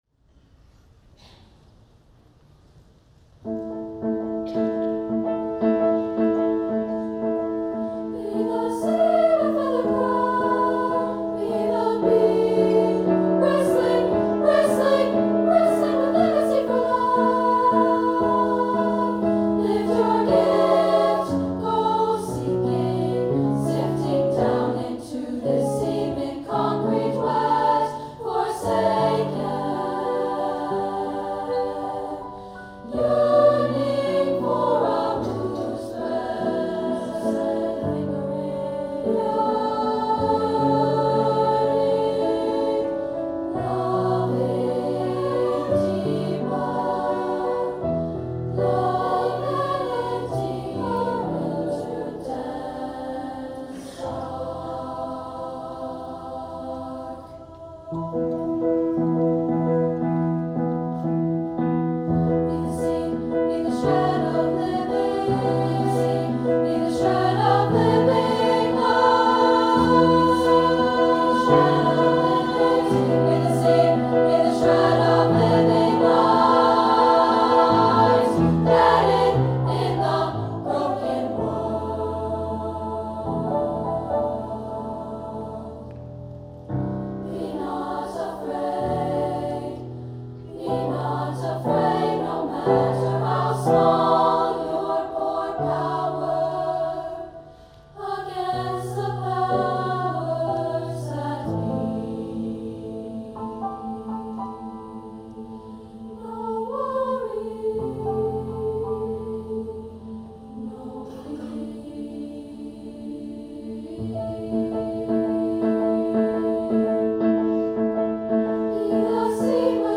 Alternating with rhythmic intensity and confident calm, "Be the Seed" is a call to courage, leadership and action in dark and challenging times.
SBMP-000 SSAA, piano 3:30 N/A